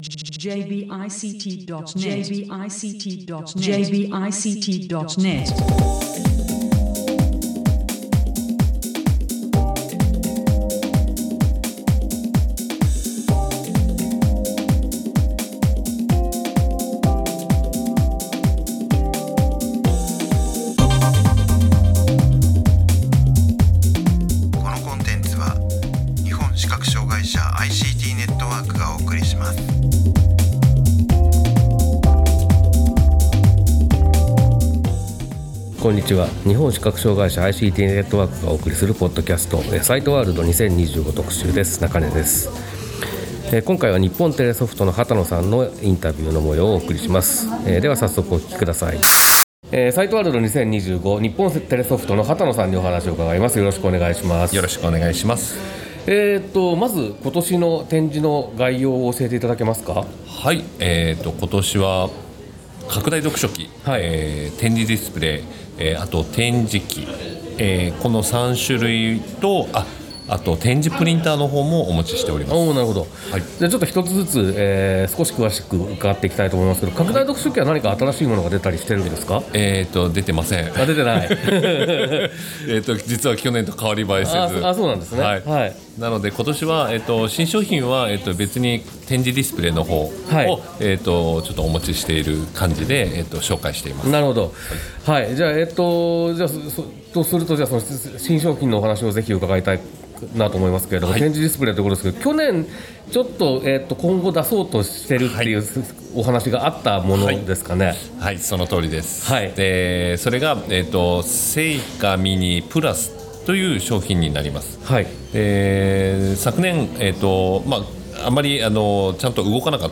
株式会社日本テレソフトのインタビューをお送りします。開発中のAndroid搭載点字ディスプレイ、清華miniPlusのお話を中心に伺っています。